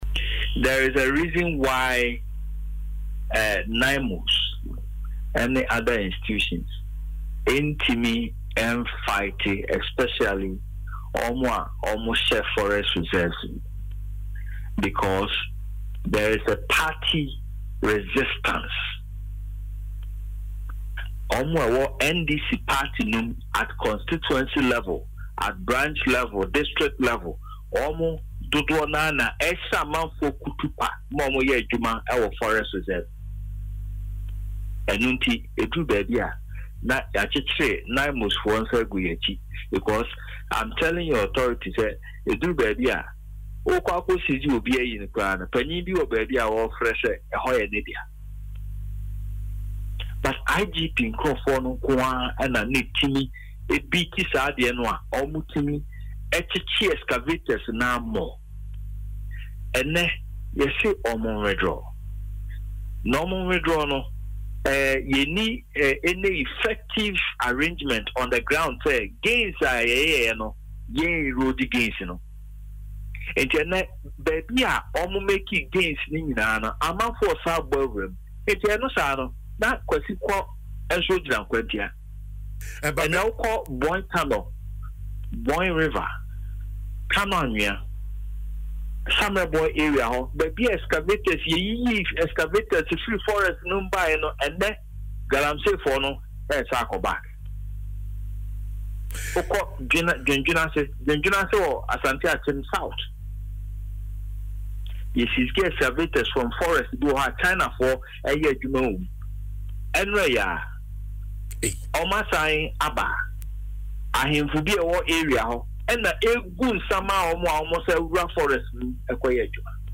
Speaking on Adom FM’s morning show, Dwaso Nsem